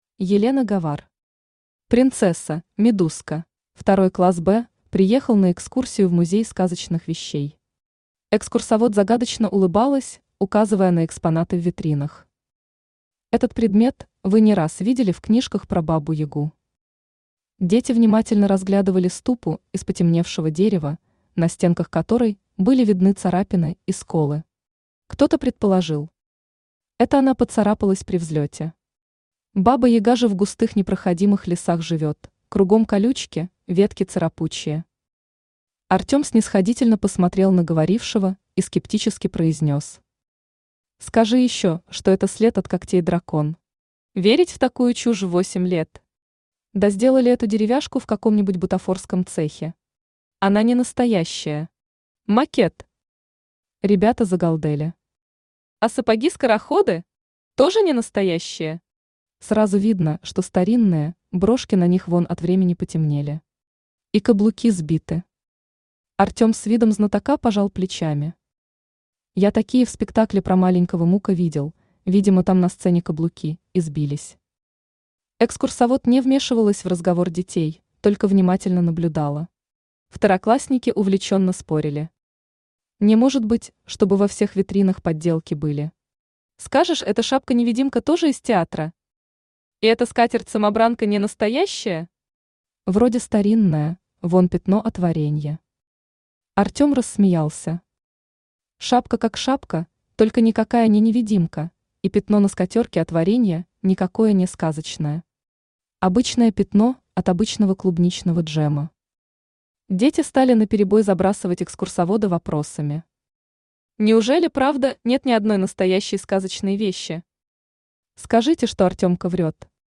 Аудиокнига Принцесса – медузка | Библиотека аудиокниг
Aудиокнига Принцесса – медузка Автор Елена Гавар Читает аудиокнигу Авточтец ЛитРес.